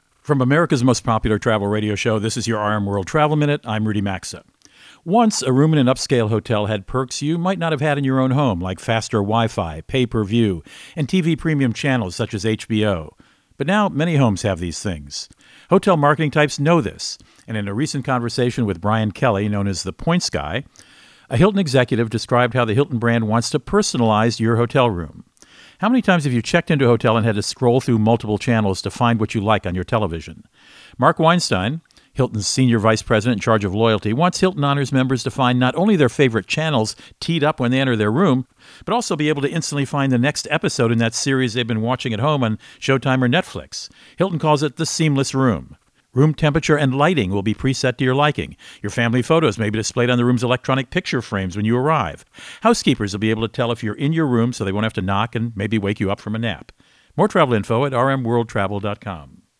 America's #1 Travel Radio Show
Co-Host Rudy Maxa | Hilton Wants to Personalize Your Room